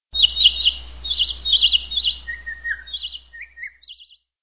birds.wav